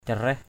/ca-rɛh/ 1. (d.) sen = lotus. lotus. bangu caraih bz~% c=rH hoa sen. lotus flower. 2.